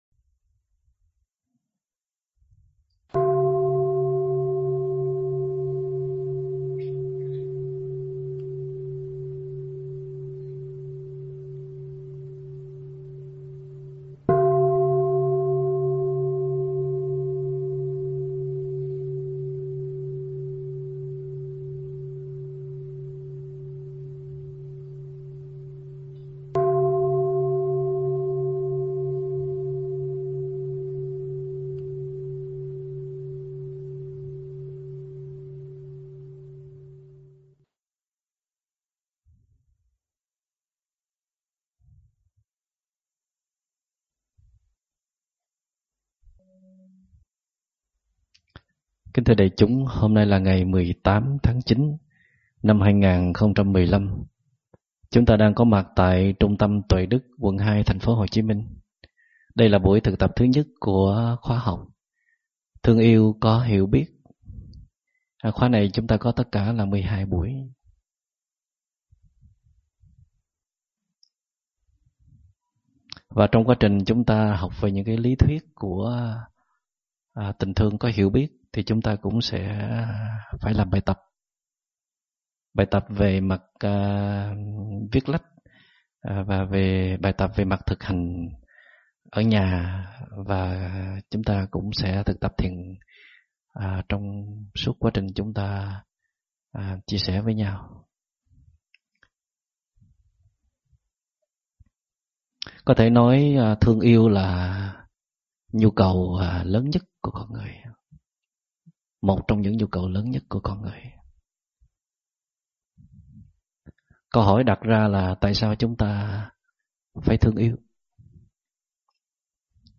Nghe mp3 thuyết pháp Tại sao ta lại thương yêu
giảng tại trung tâm Tuệ Đức ngày 18 tháng 9 năm 2015